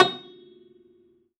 53x-pno15-A5.wav